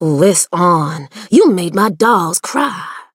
BS_juju_hurt_vo_06.mp3